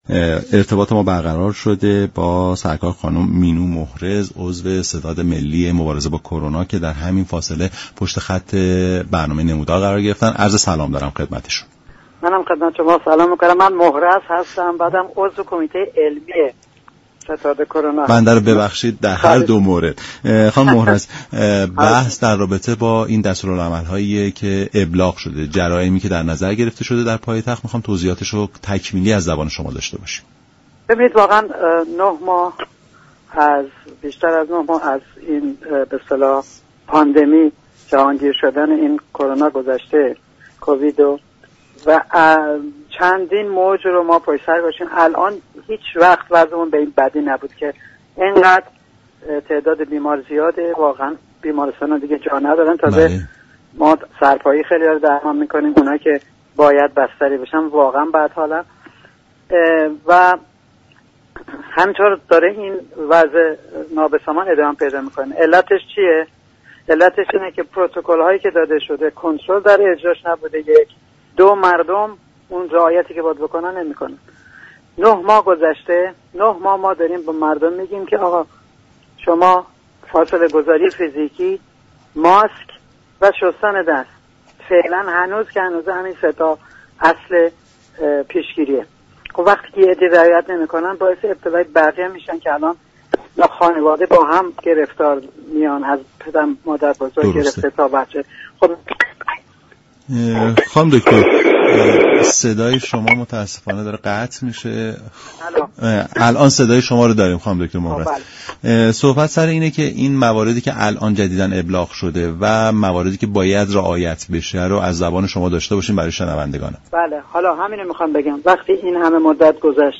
دكتر مینو محرز در برنامه نمودار گفت: استفاده از ماسك برای همه اجباری است و افراد در ازای هر گونه تخلف باید جریمه پرداخت كنند.